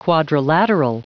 Prononciation du mot quadrilateral en anglais (fichier audio)
Prononciation du mot : quadrilateral
quadrilateral.wav